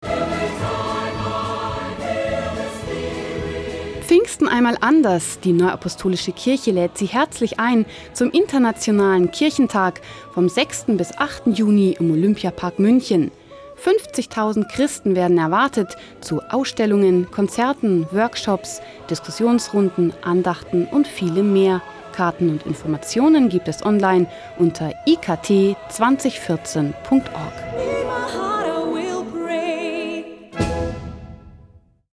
IKT-Hörfunktrailer
IKT_Hoerfunk-Trailer.WAV